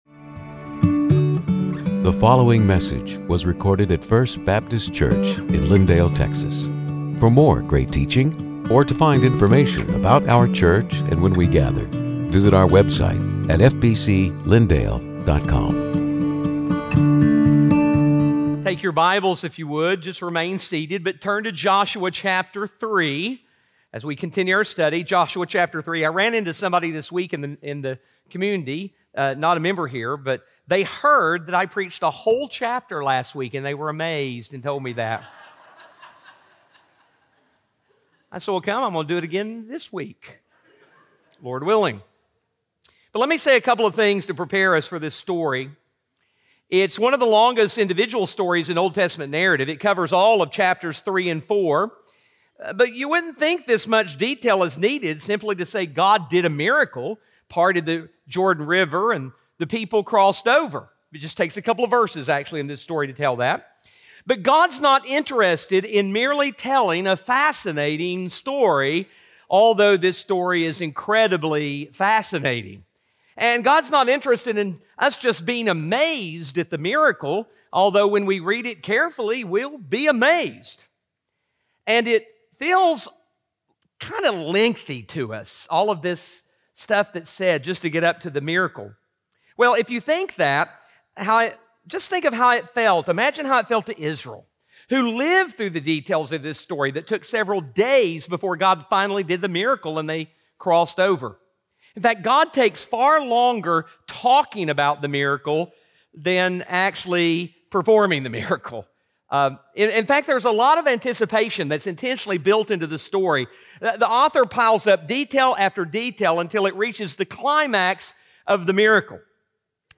Sermons › Joshua 3:1-17